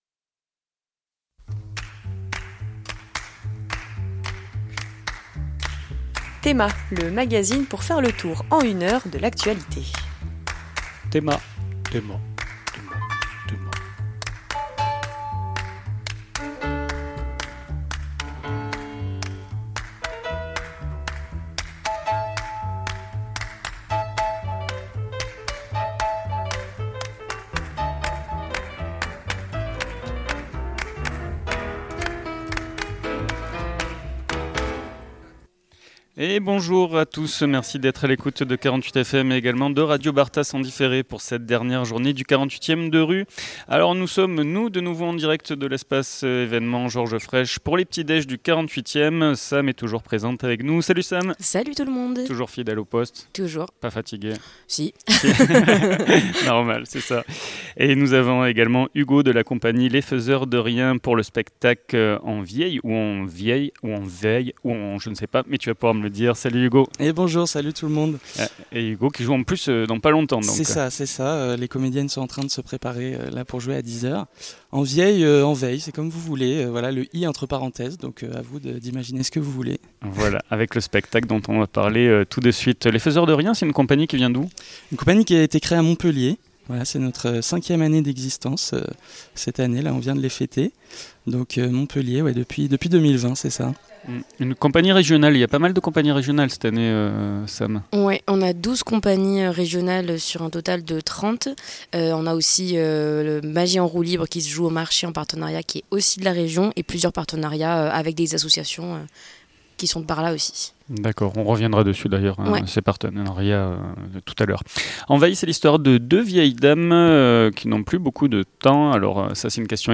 Emission du dimanche 6 juillet 2025 en direct de l’espace événement Georges Frêche